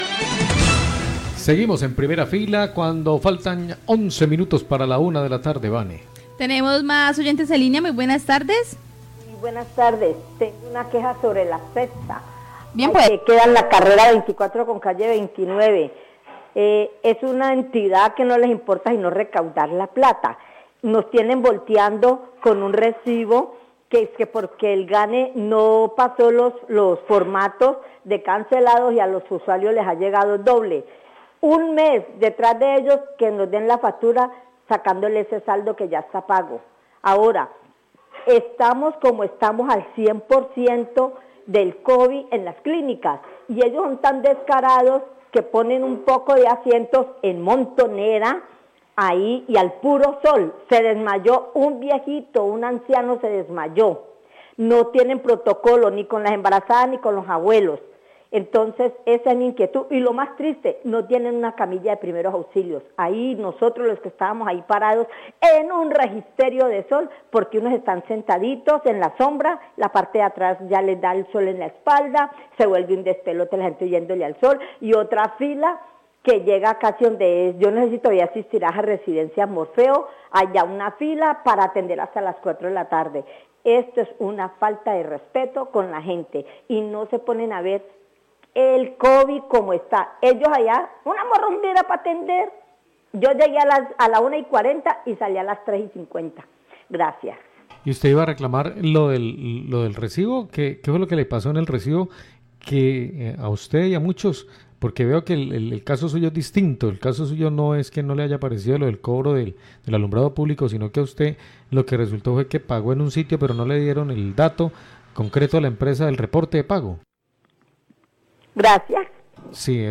Radio
queja oyentes